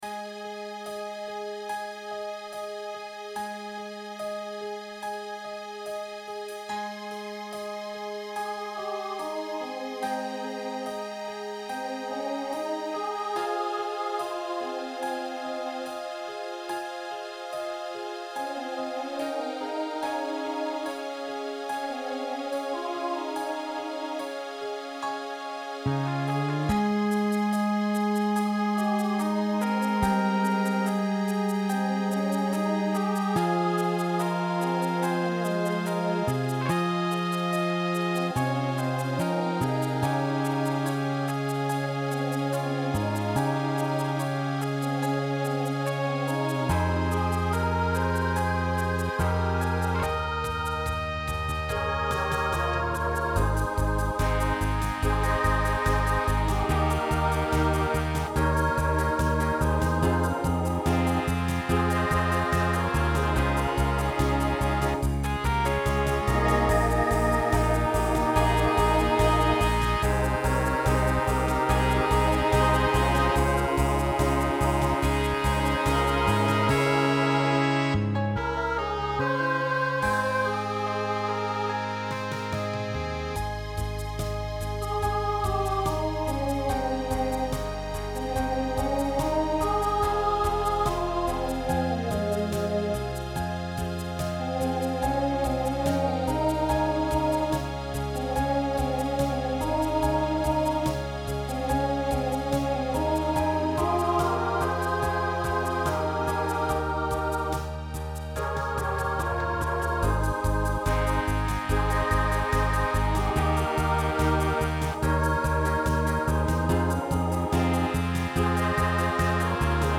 New SATB voicing for 2020